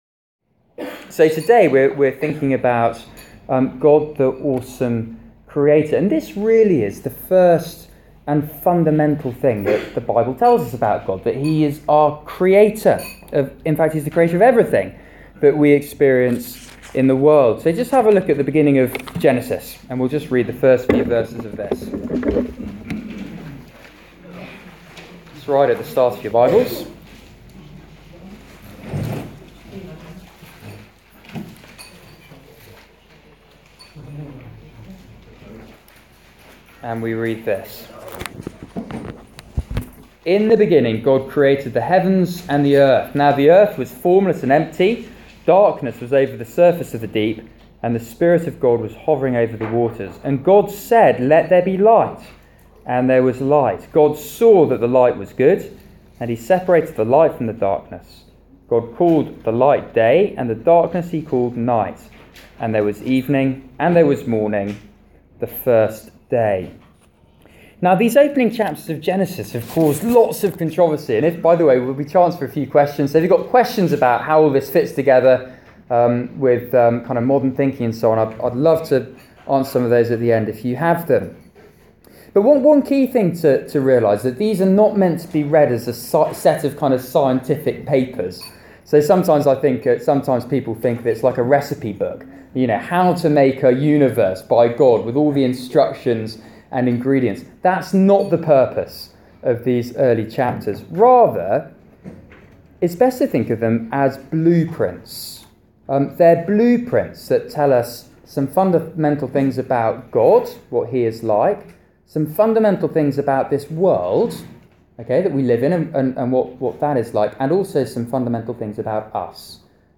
Theme: The loving creator God Loving Creator Talk